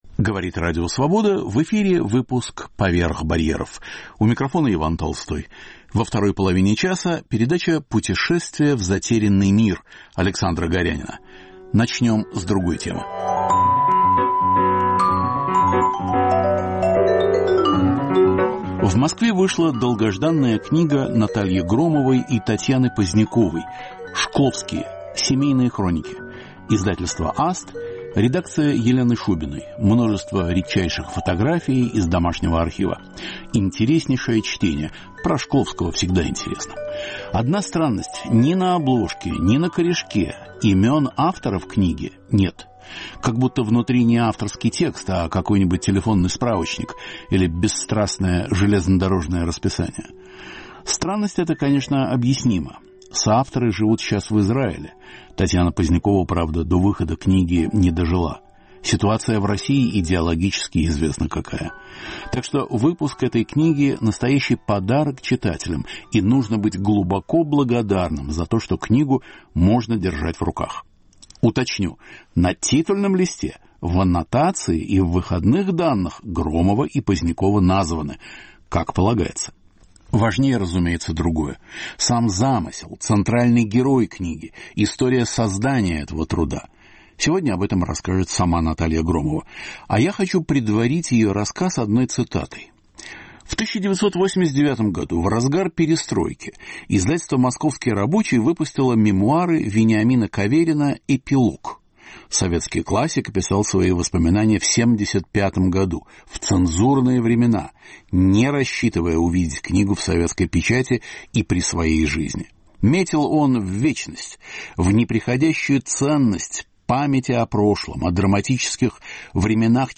Звучит запись выступления
на вечере в Праге (май 2025).